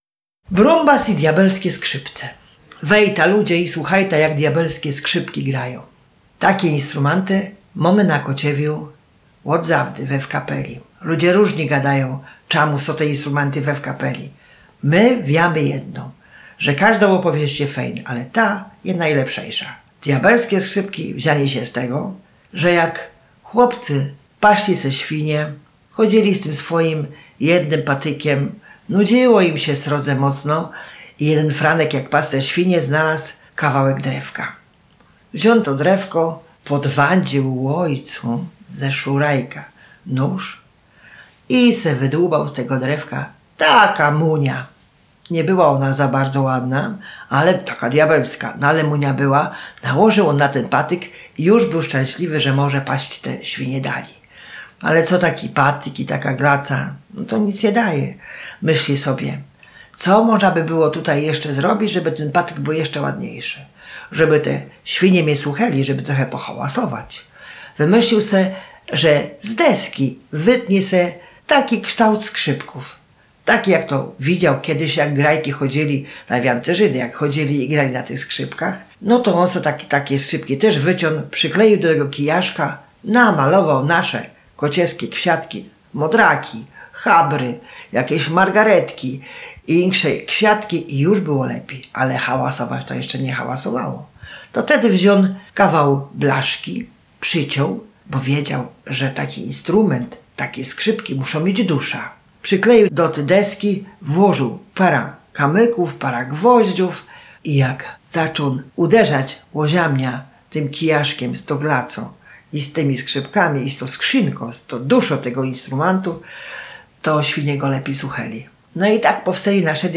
Bajka „O diabelskich skrzypcach i burczybasie”.